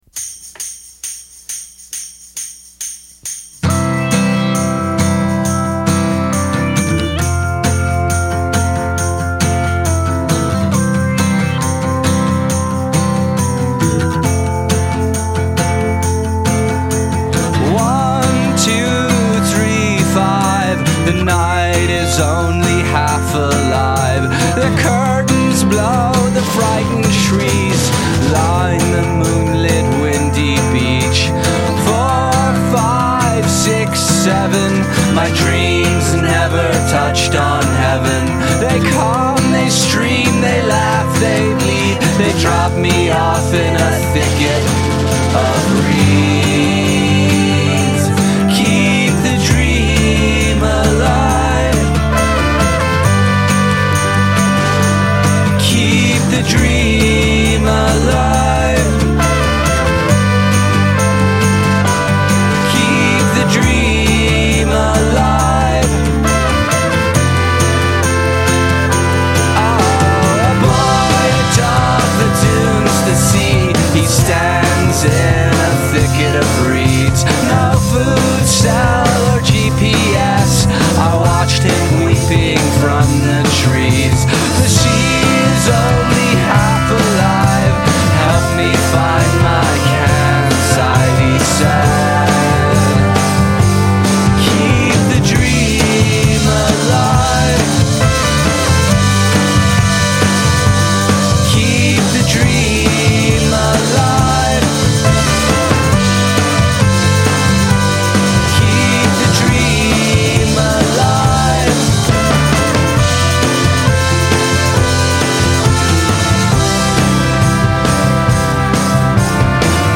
His songs are literate, his arrangements imaginative.
He’s got a good voice. I like the horns, too!